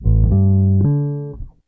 BASS 17.wav